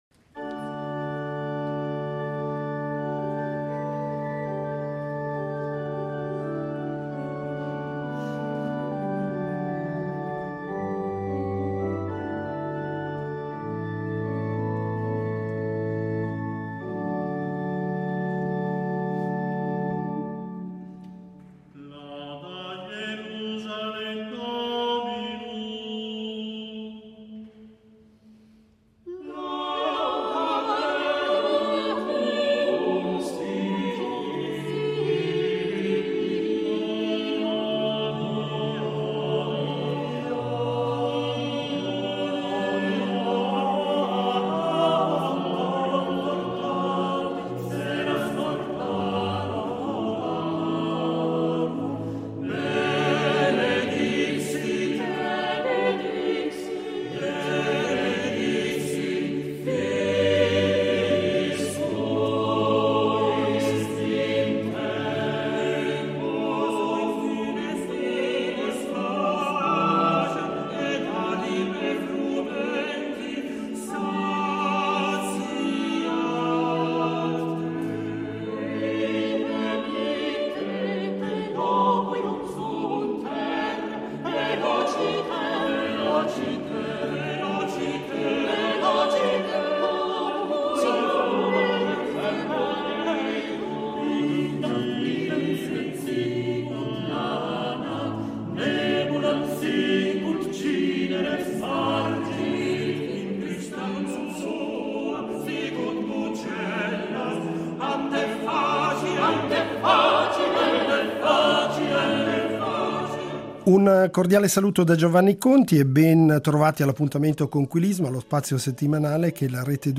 Un Vespro per due Chiese Gemelle nel nome della Theotókos
Il concerto propone musiche polifoniche e monodiche di differente provenienza e di vari autori sia nello stile di ampia polifonia, eseguita in San Marco, sia di cromatici melismi bizantini che da secoli hanno fatto eco sotto le cupole di Aghia Sophia.